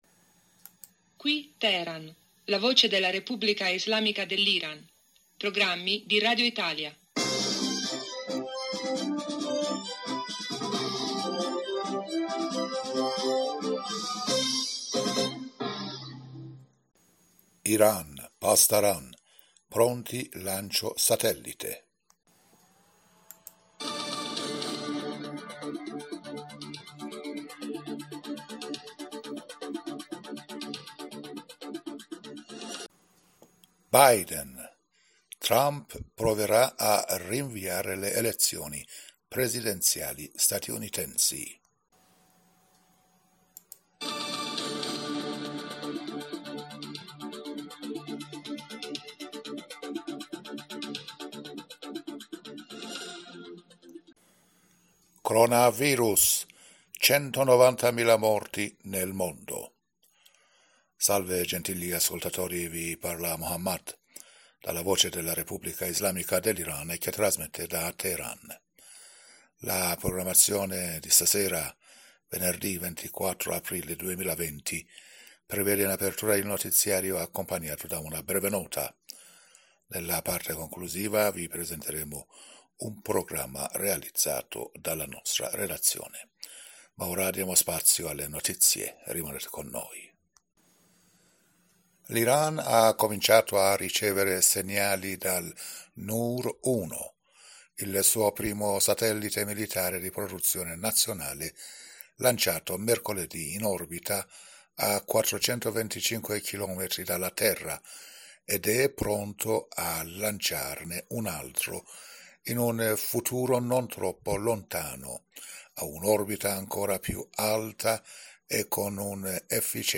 Giornale radio venerdi sera 24 aprile 2020